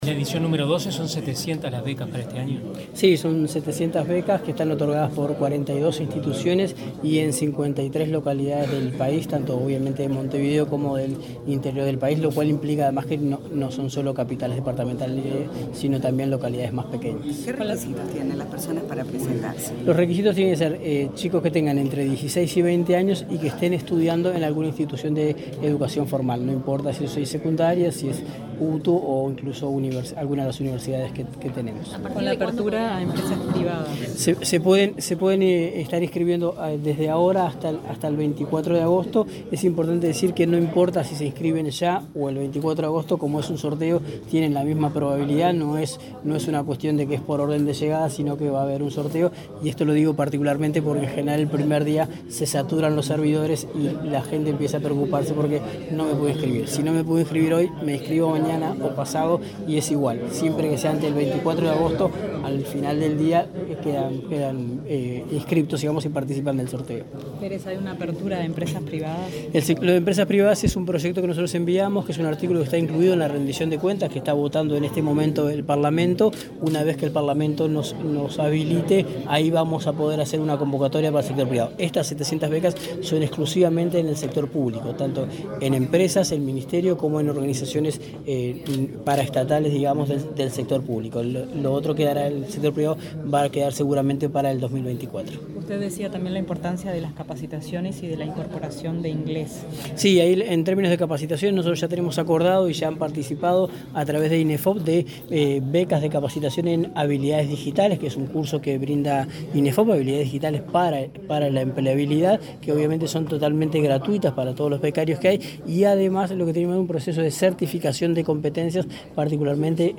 Declaraciones del director nacional de Empleo
Este jueves 17, el director nacional de Empleo, Daniel Pérez, dialogó con la prensa, luego de participar en la presentación de la 12.ª edición del